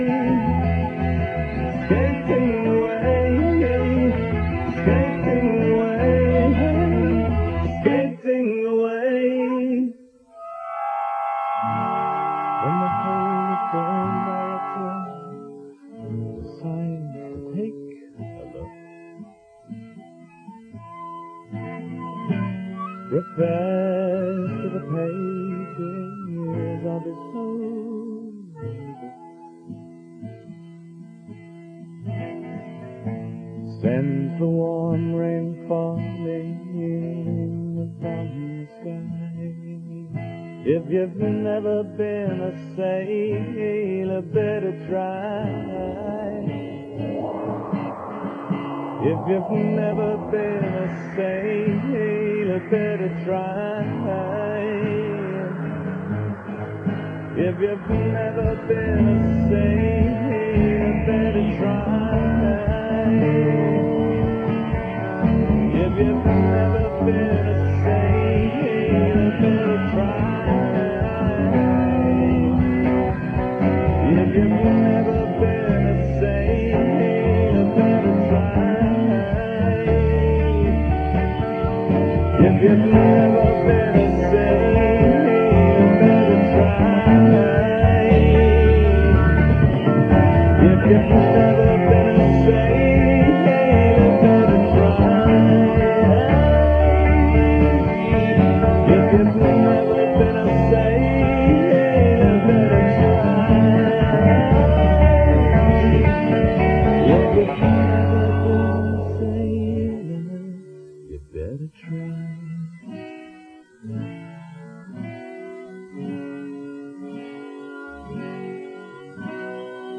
(The last phrase is repeated over and over again)